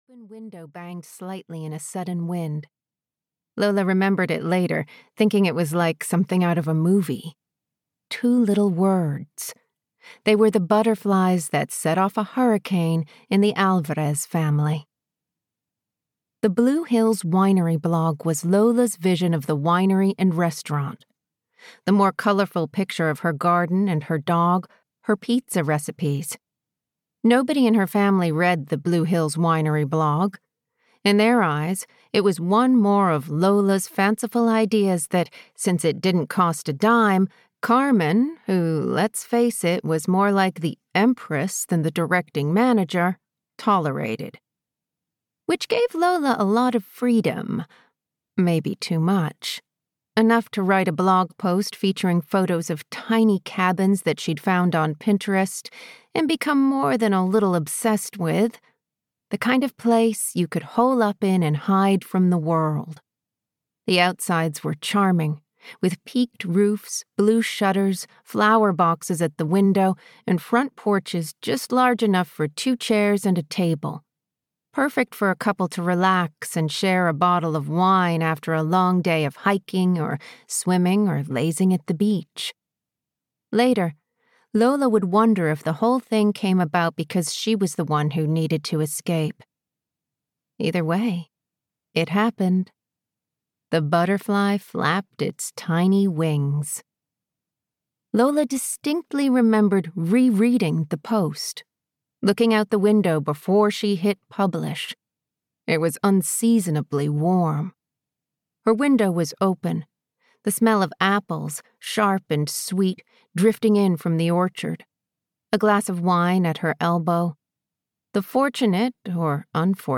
Long Walk Home (EN) audiokniha
Audiobook Long Walk Home written by Ellyn Oaksmith.
Ukázka z knihy